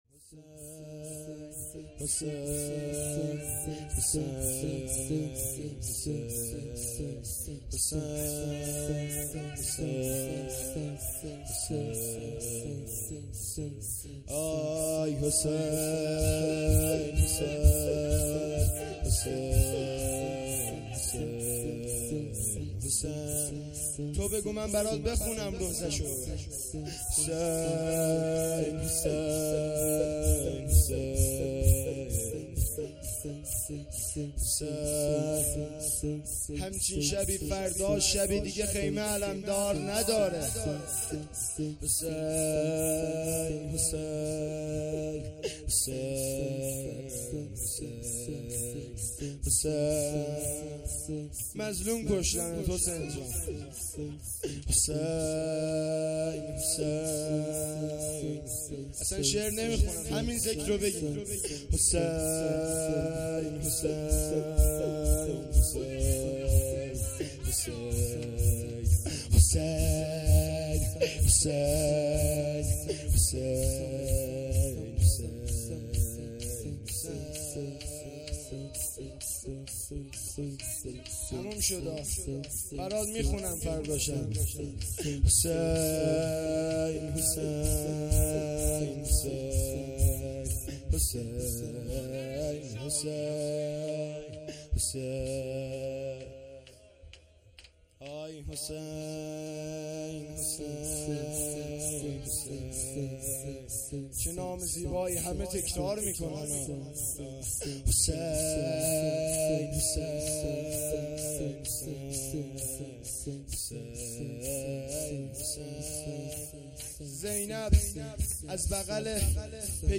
شور
شب هشتم محرم الحرام ۱۴۴۳